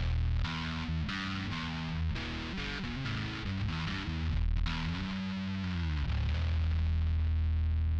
Quick recordings, rusty playing of 6 strings Harley Benton bass, passive mode.
American Tone with drive